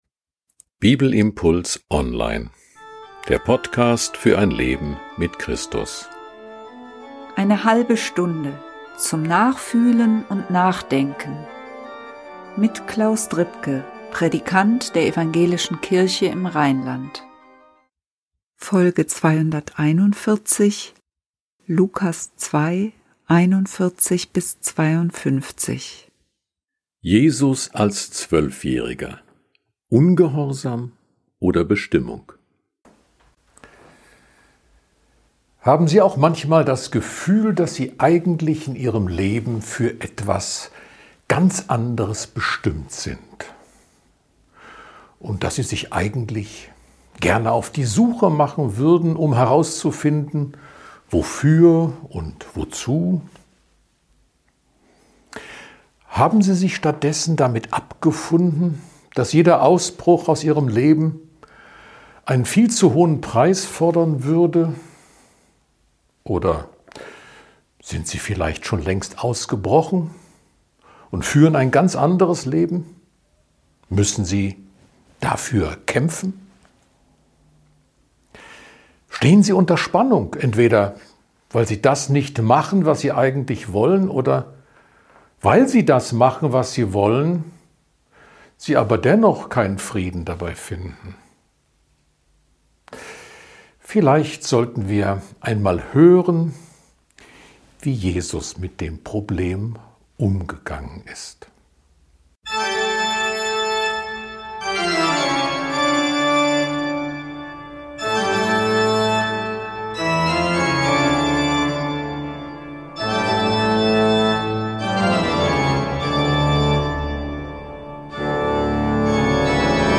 Ein Bibelimpuls zu Lukas 2, 41-52.